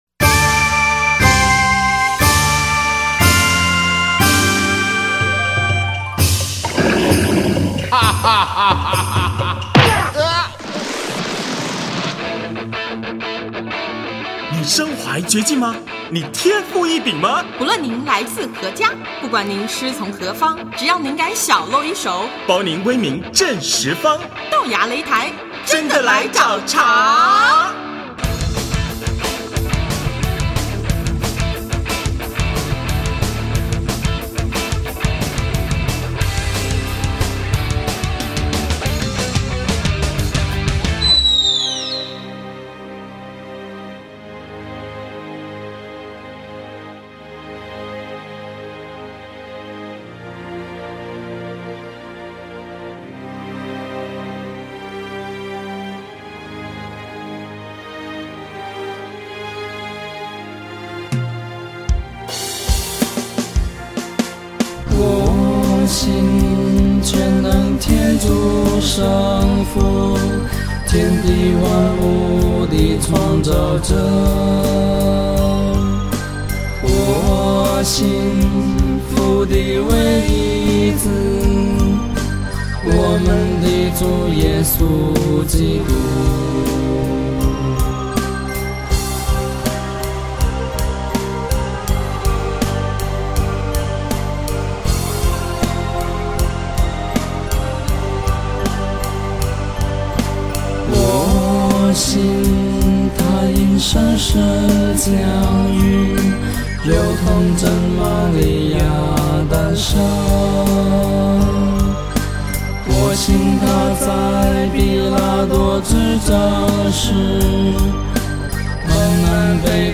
【豆芽擂台】218|专访望乐团(三)：用尽力量